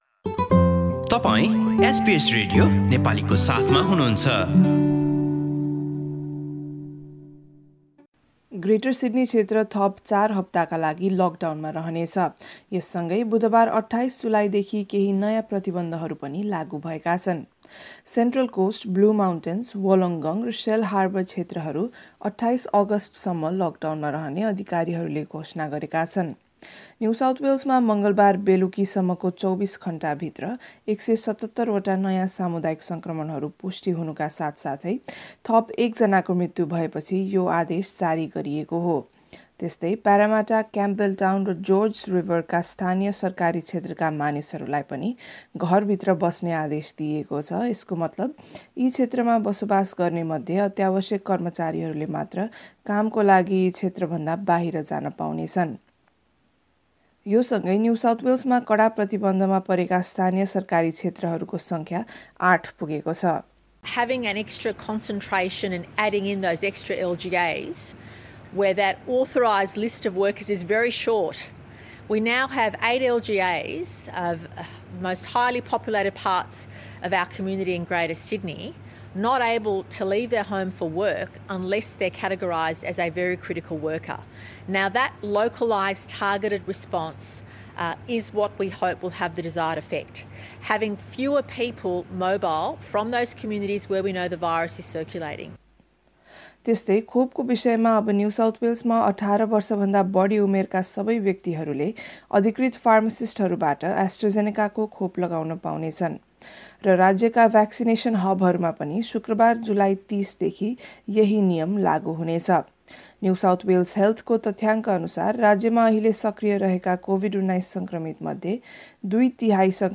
रिपोर्ट सुन्नुहोस्: null हाम्रा थप अडियो प्रस्तुतिहरू पोडकास्टका रूपमा यहाँबाट नि:शुल्क डाउनलोड गर्न सक्नुहुन्छ।